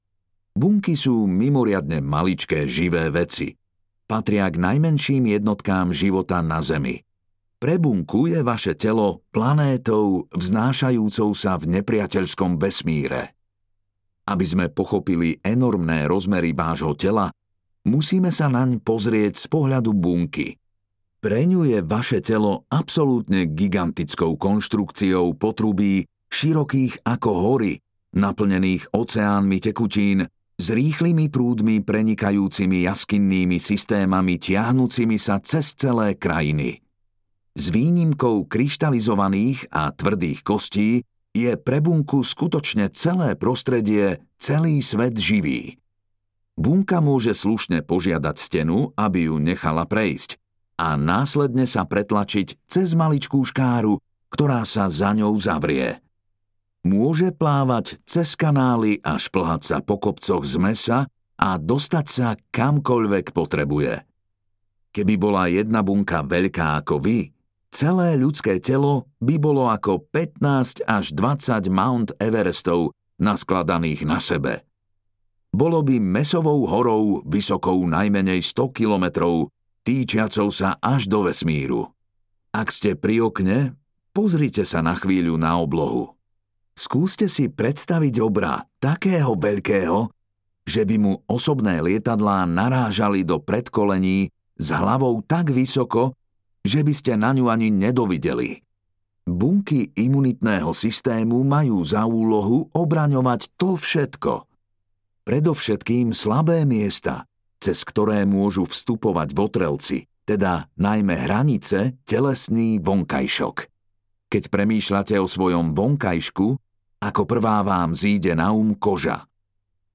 Imunita audiokniha
Ukázka z knihy